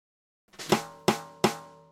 快速时髦的爵士鼓 小鼓 125 Bpm
Tag: 125 bpm Jazz Loops Drum Loops 330.92 KB wav Key : Unknown